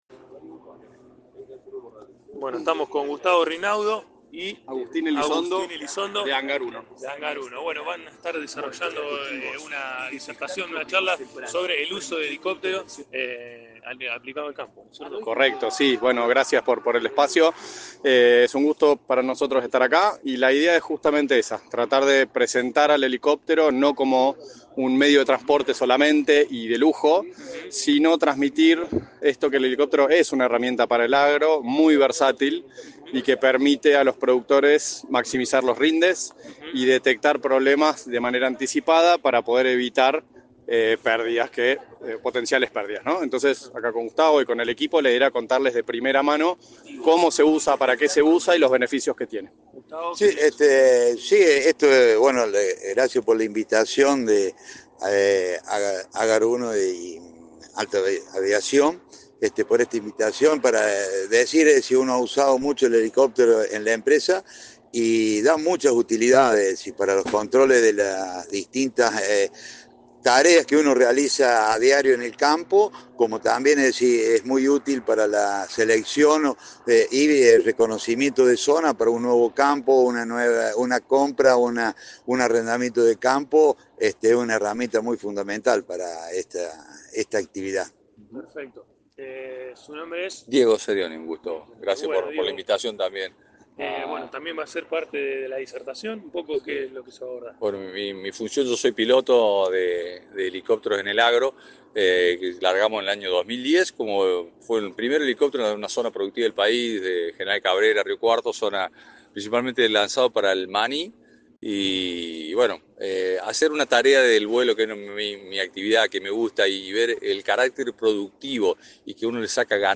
De la mano de Hangar 1 y Alta Aviación se llevó a cabo este sábado, en el anfiteatro de la Tecnoplaza, una interesante charla sobre el helicóptero como herramienta para el agro.